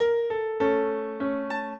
minuet10-5.wav